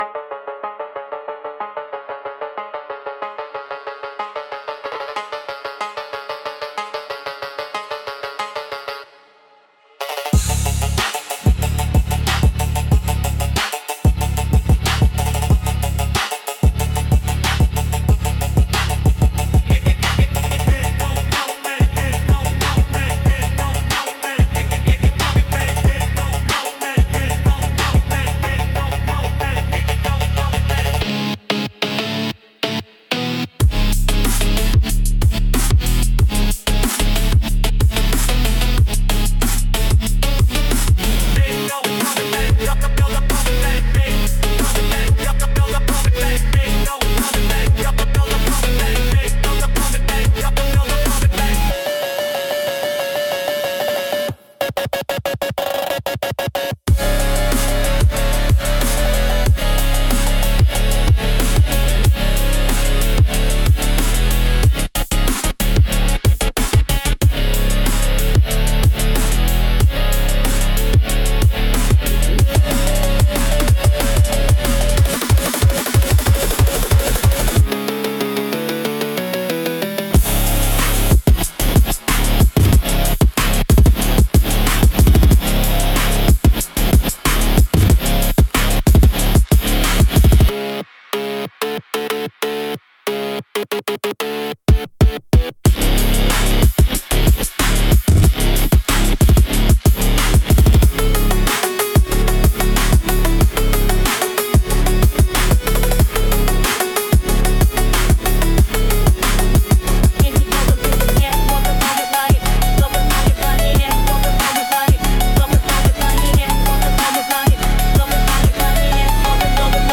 Genre: Phonk Mood: Battle Scene Editor's Choice